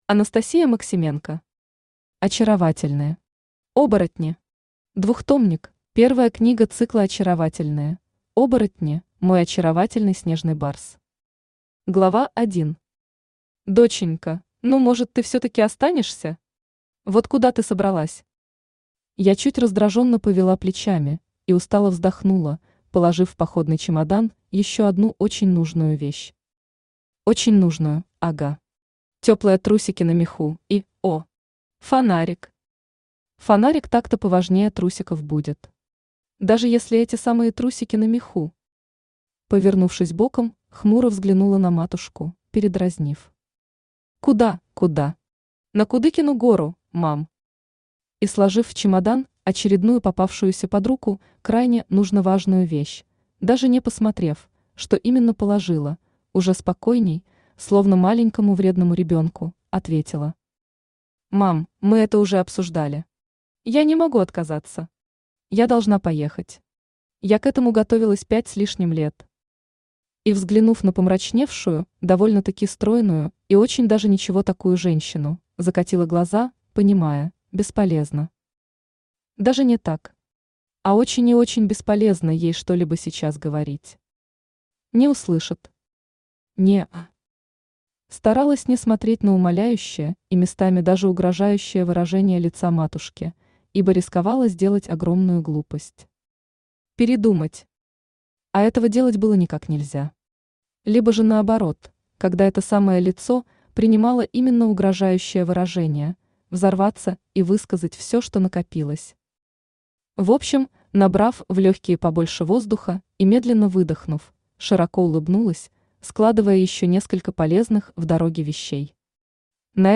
Двухтомник Автор Анастасия Максименко Читает аудиокнигу Авточтец ЛитРес.